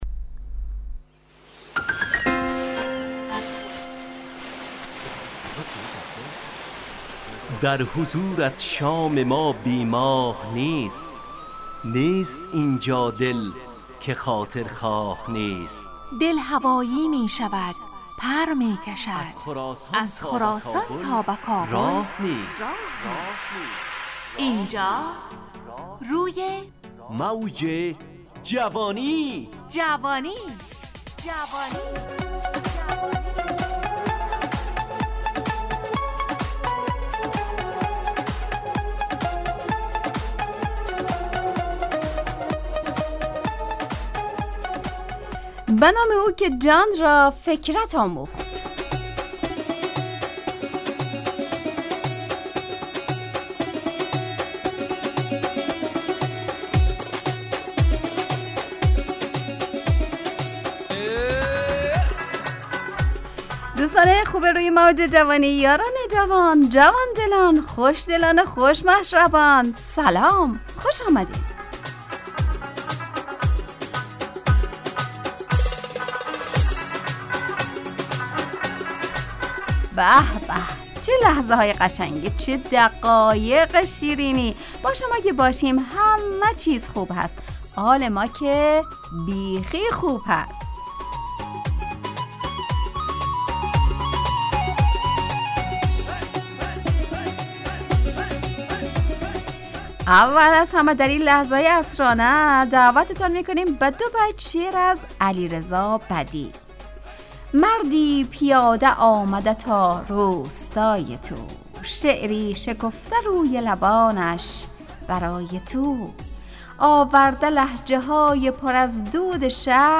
همراه با ترانه و موسیقی .